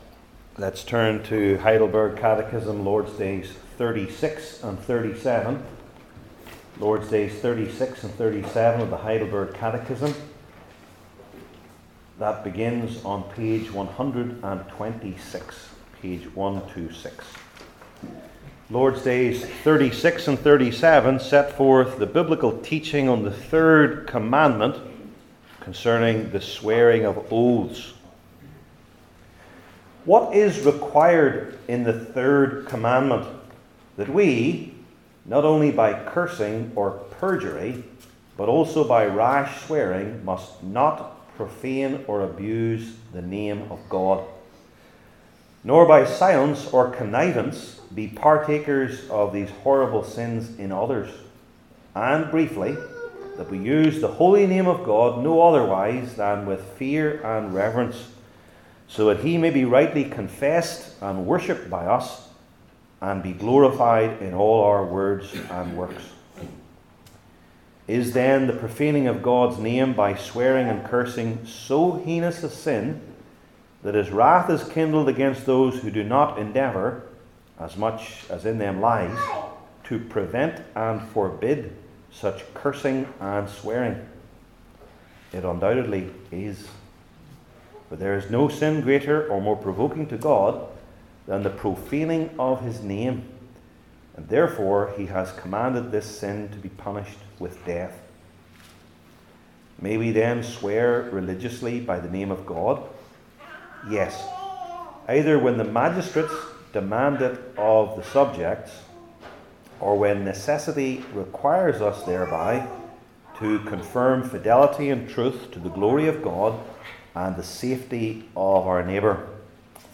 The Ten Commandments Passage: Matthew 26:57-75 Service Type: Heidelberg Catechism Sermons I. The Events II.